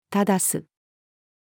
正す-female.mp3